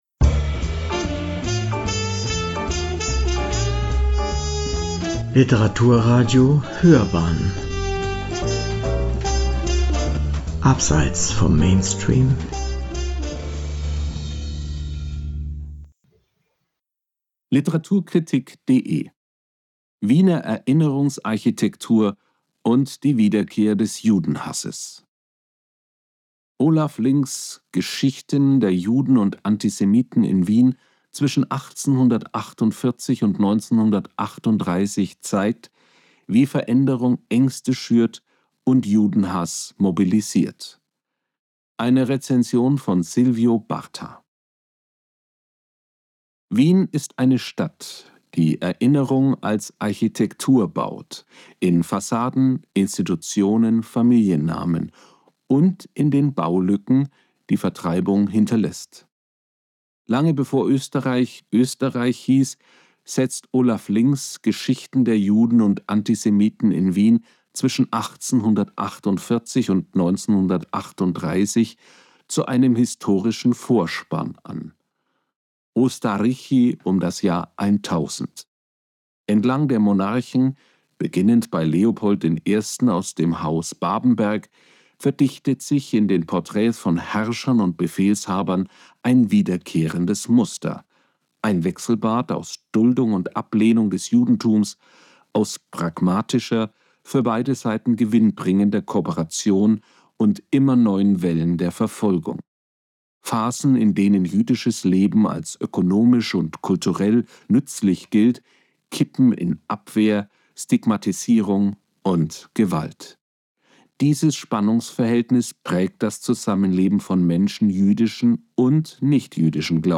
Eine Rezension